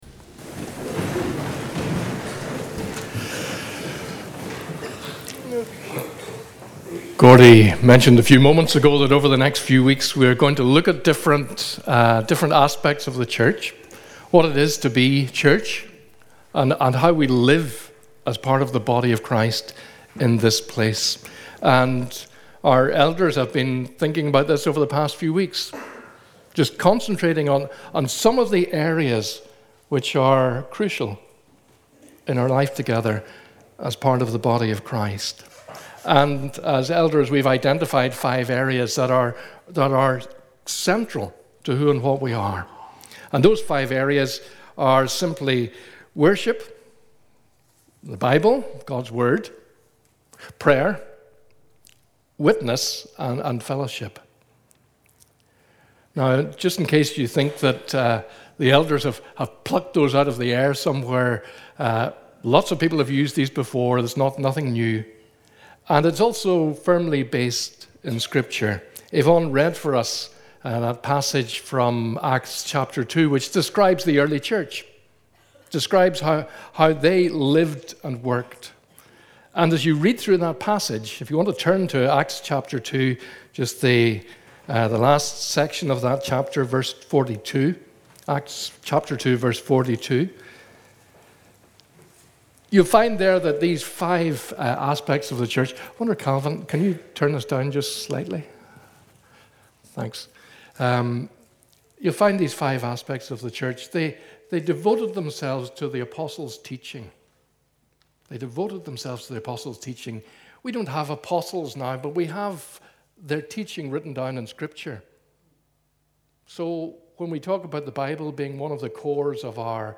Sermon Archive - Ballywillan Presbyterian Church